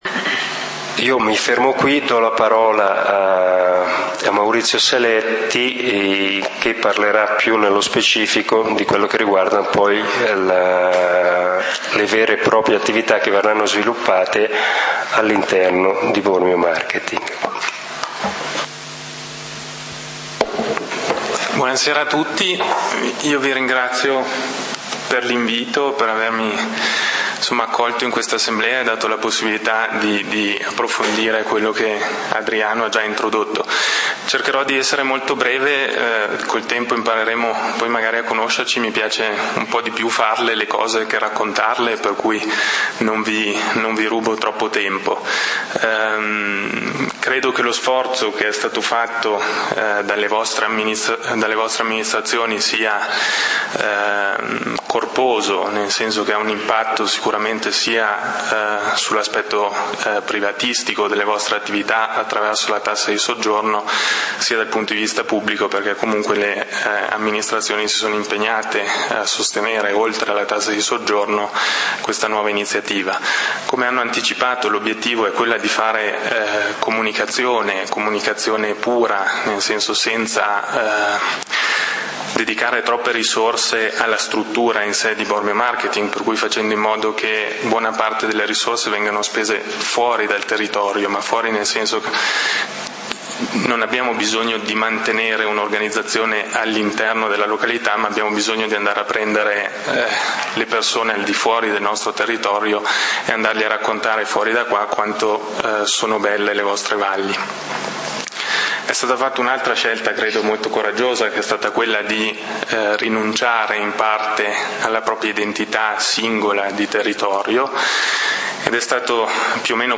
Assemblea pubblica del 14 Ottobre 2013 - Turismo : informazione, confronto ed approfondimento